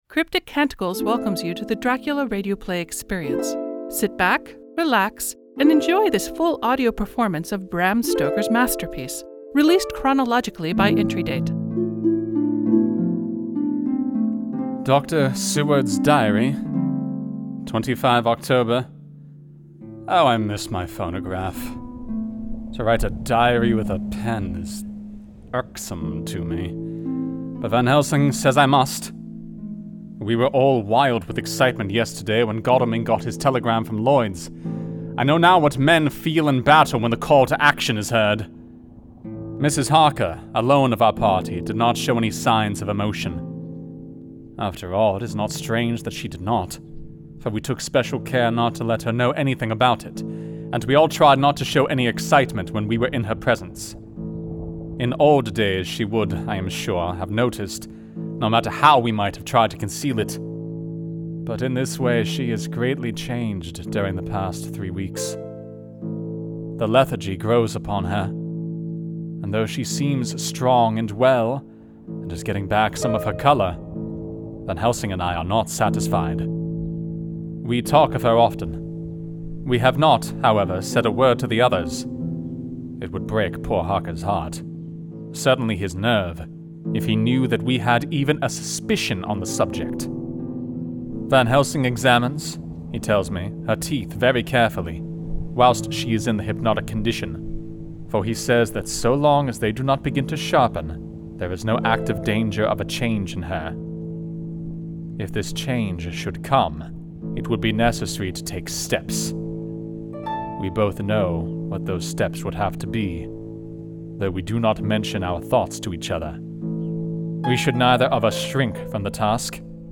Dr. Seward
Audio Engineer, SFX and Music
This is a rebroadcast of the original 2017 work.